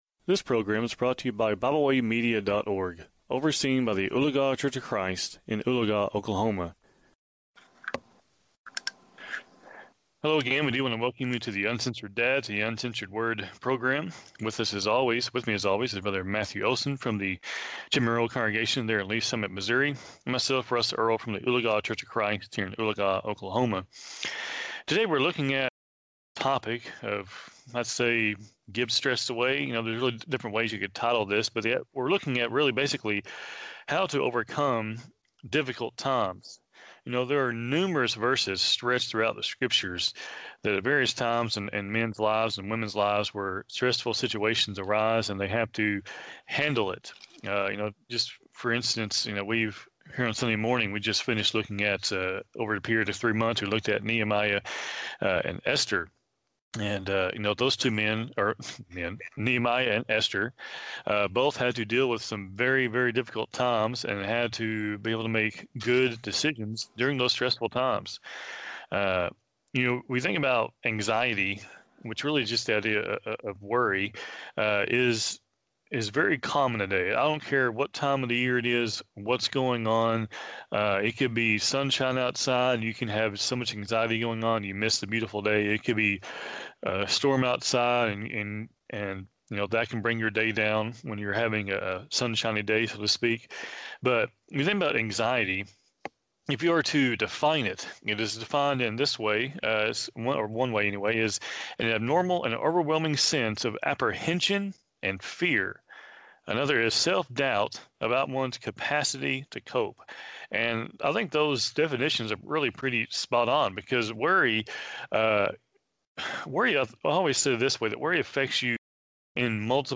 Program Info: Live program from the TGRN studio in Mount Vernon, TX